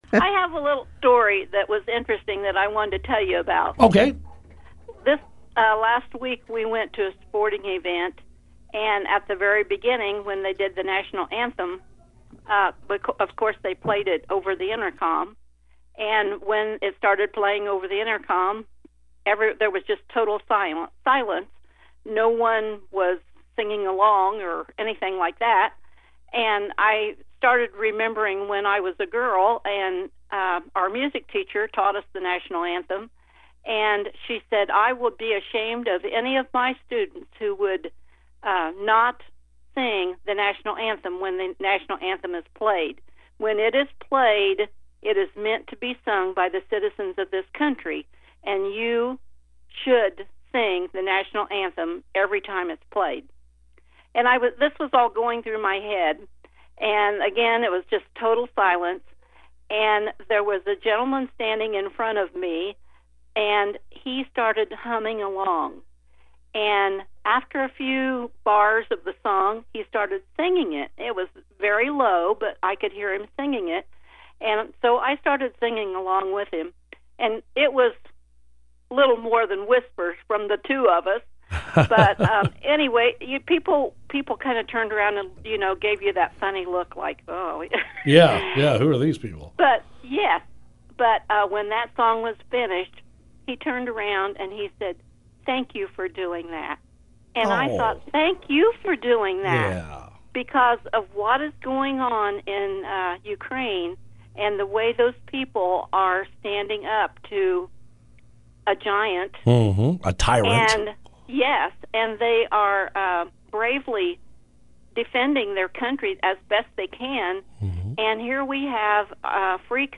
A caller to Party Line Wednesday morning reminded us it’s always the right time to sing the National Anthem.